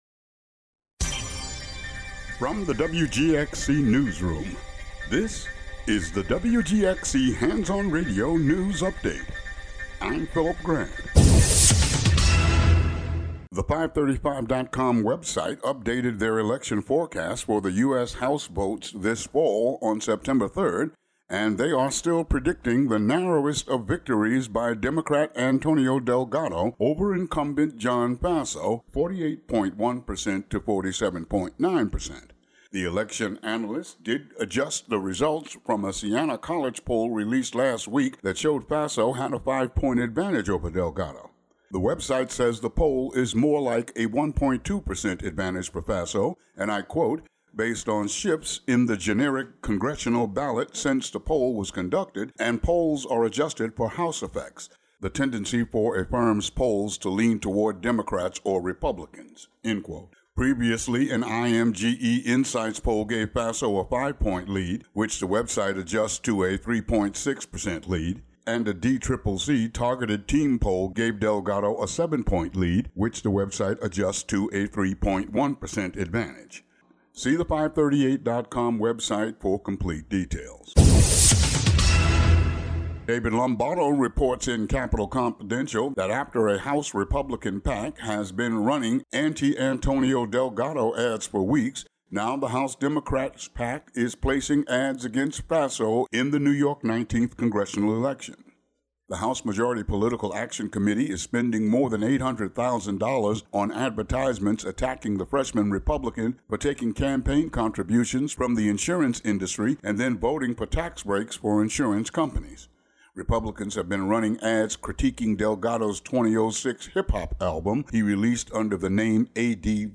WGXC Local News Update Audio Link
Daily headlines for WGXC.